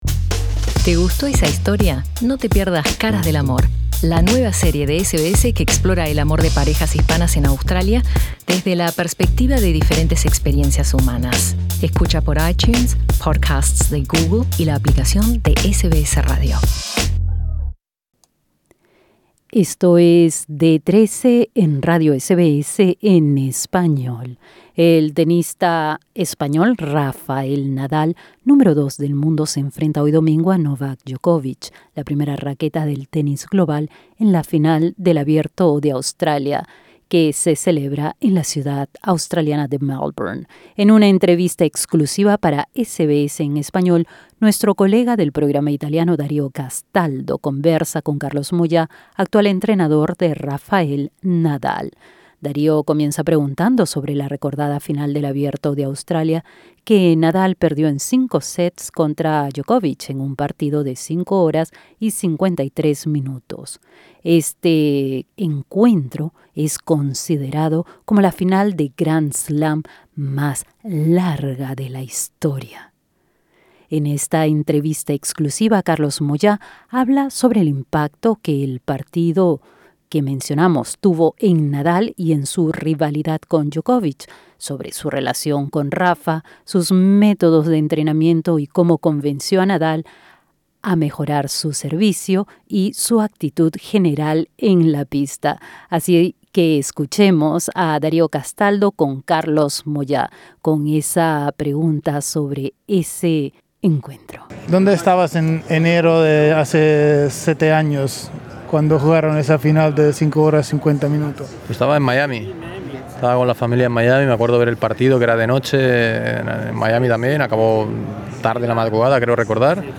En una entrevista exclusiva para SBS en Español